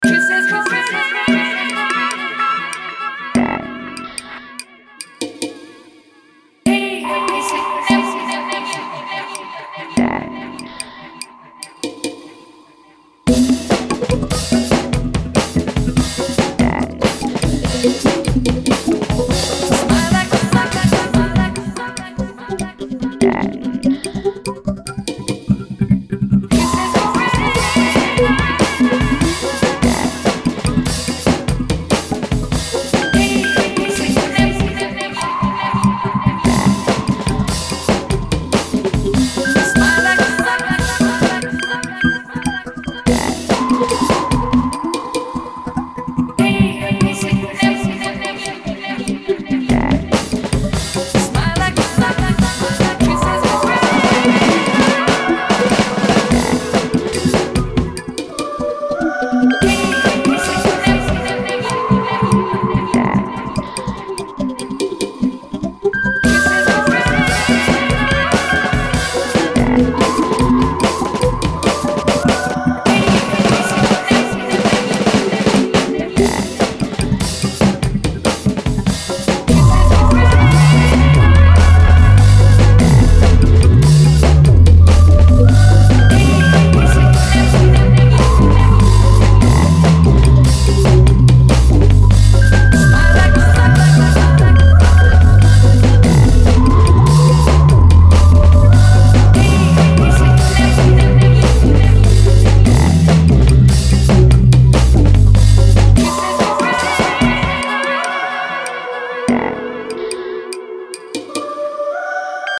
Honestly, I need a drumbeat here. can't make it work.
Ambient